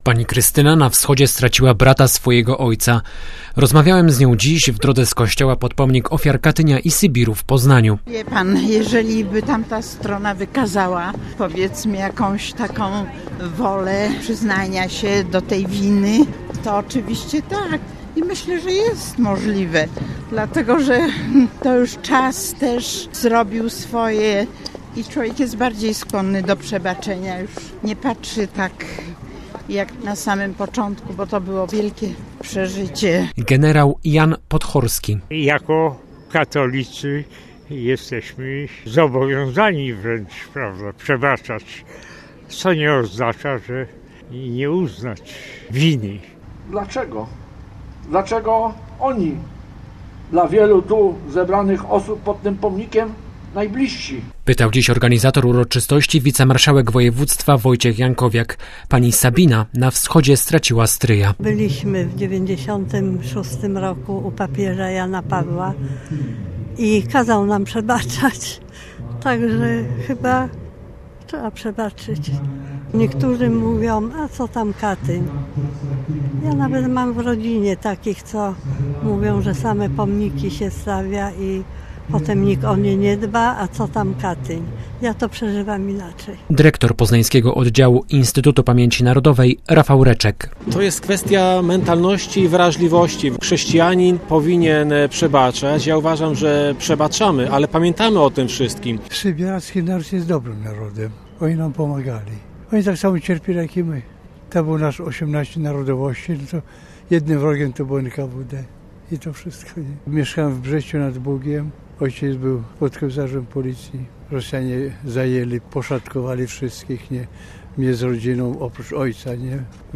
77. rocznicę Zbrodni Katyńskiej uczczono dziś w Poznaniu. W czasie modlitwy w kościele Ojców Dominikanów biskup Grzegorz Balcerek mówił między innymi o przebaczeniu.
W kościele Ojców Dominikanów odprawiono mszę w intencji Polaków pomordowanych na Wschodzie. Jej uczestnicy po nabożeństwie przeszli pod Pomnik Ofiar Katynia i Sybiru, gdzie odbyły się główne uroczystości.